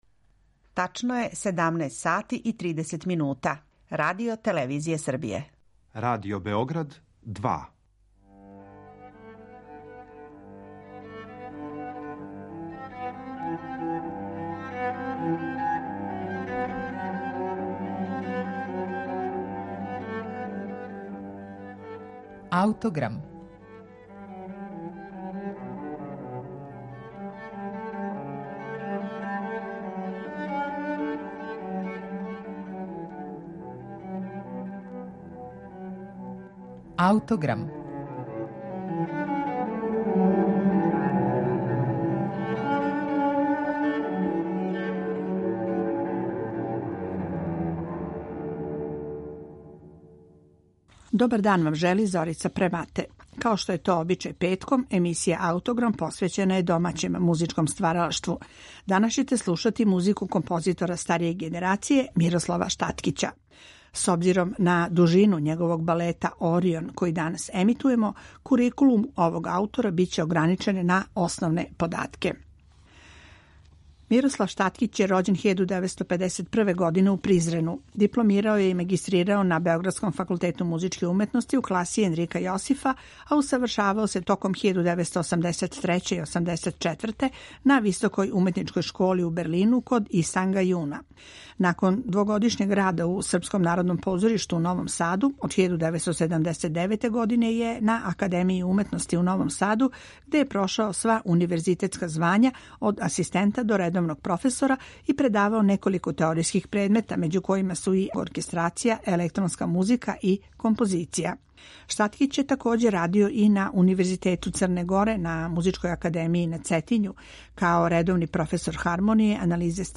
интегралну верзију балета